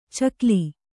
♪ cakli